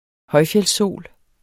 Udtale [ ˈhʌjfjεls- ]